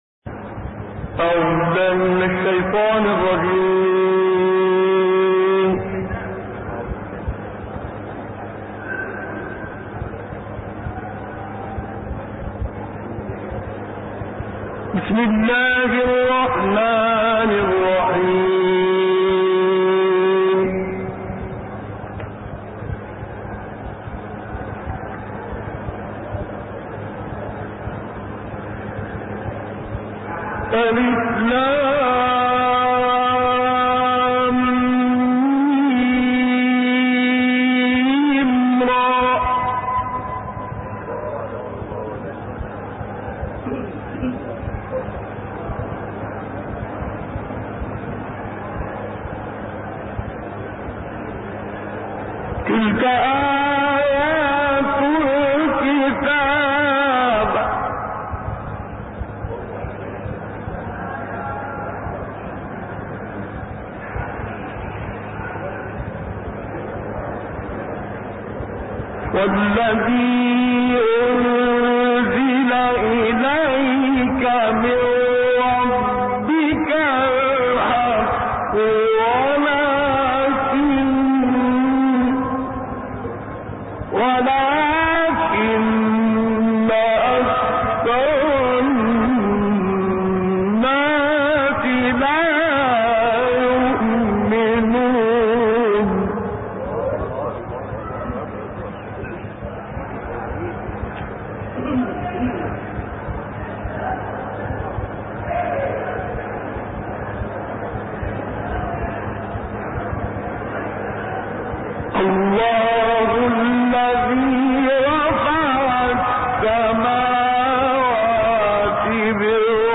گروه شبکه اجتماعی: نغمات صوتی با صدای قاریان برجسته مصری ارائه می‌شود.